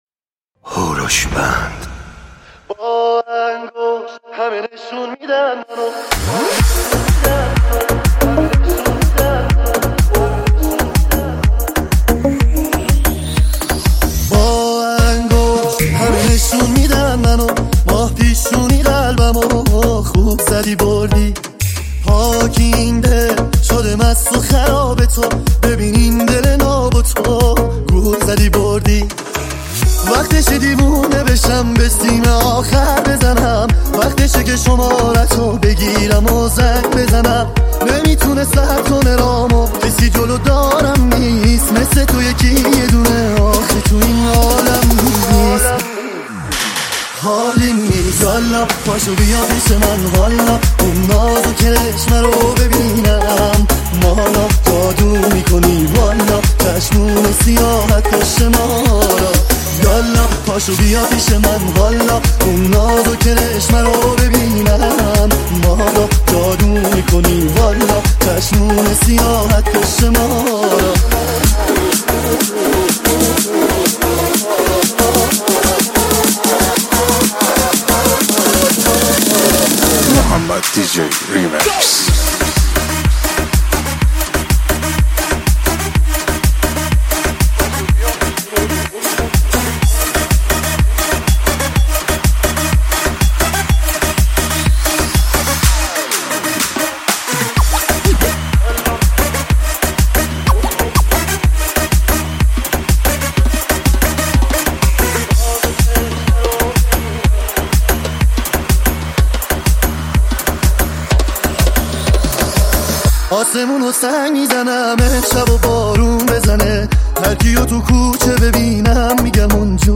ریمیکس شاد رقص و دنس
ریمیکس شاد مخصوص عروسی و رقص